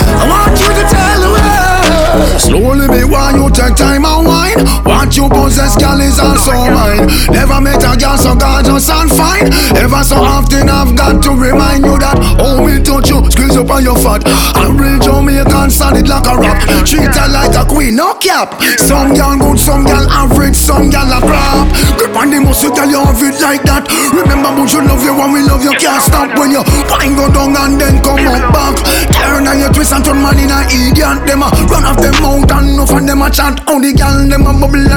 Off-beat гитары и расслабленный ритм
Жанр: Регги